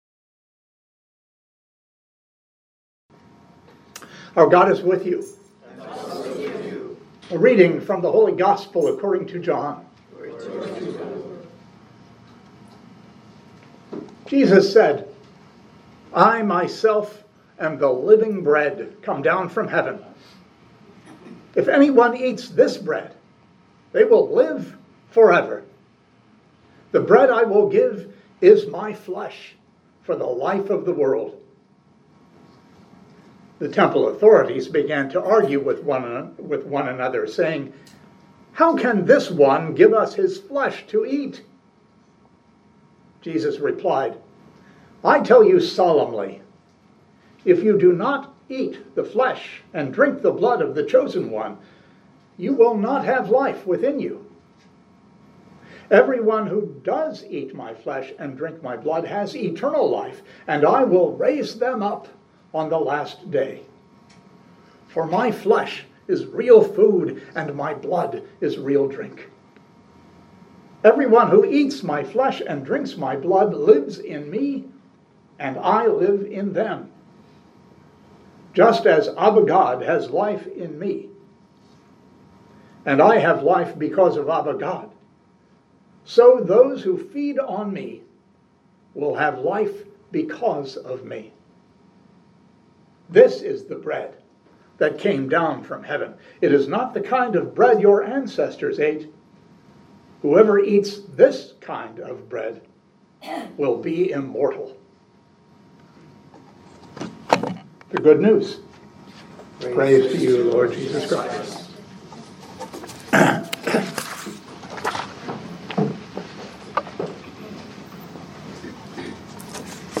Living Beatitudes Community Homilies: God is Food